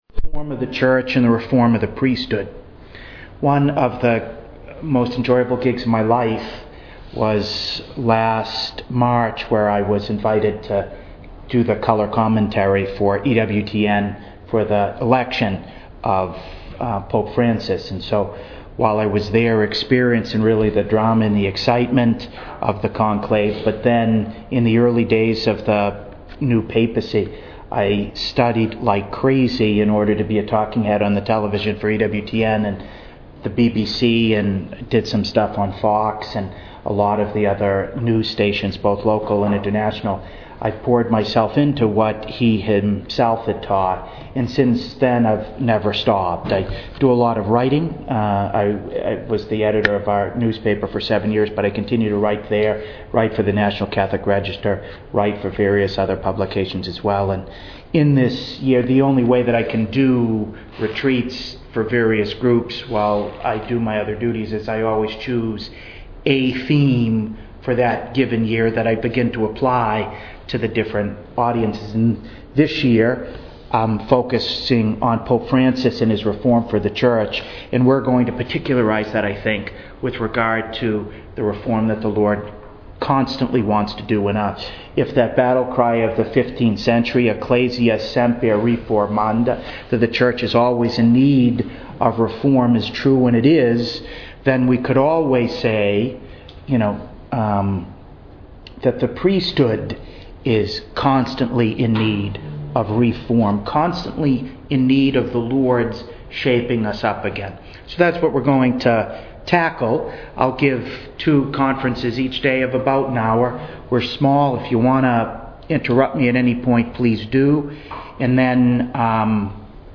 To listen to an audio recording of this introductory conference, please click below: